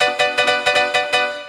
hous-tec / 160bpm / piano